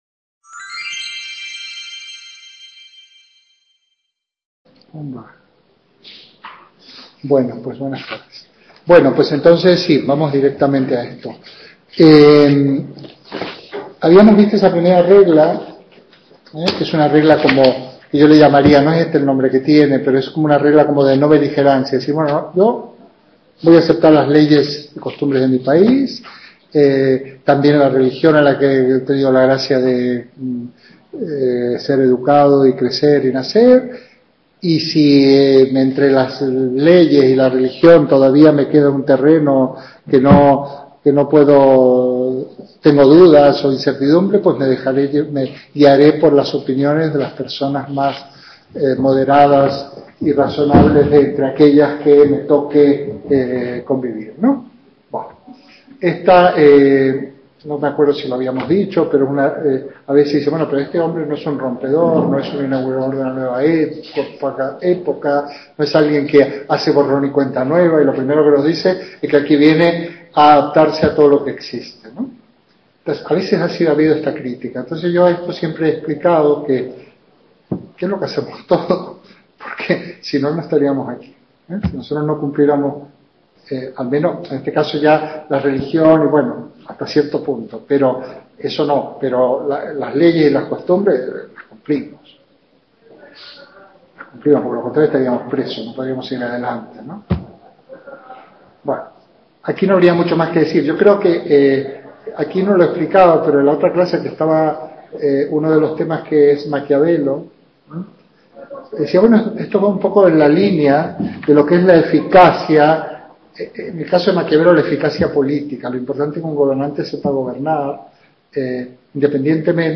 clase presencial